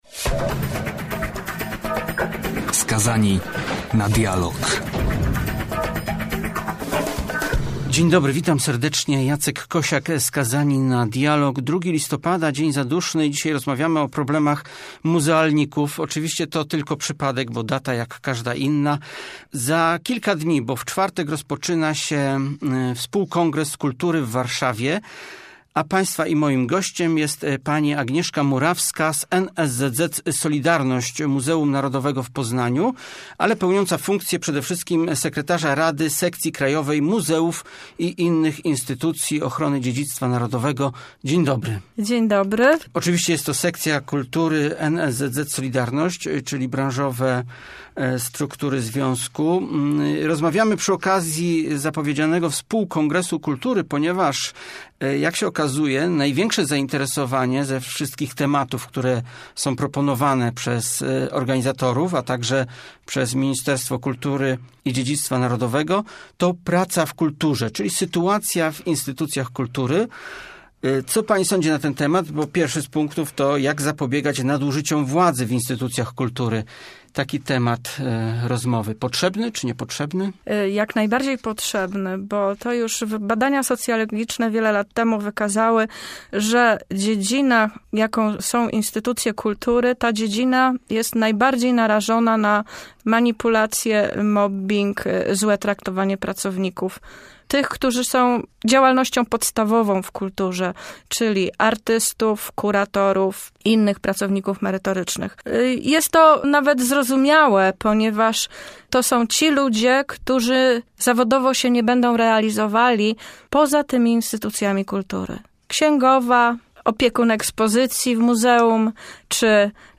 Rozmowa przed debatami współKongresu Kultury.